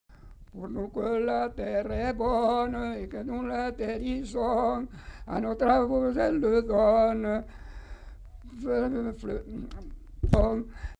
Genre : chant
Effectif : 1
Type de voix : voix d'homme
Production du son : chanté
• [enquêtes sonores] Répertoire de chants du Savès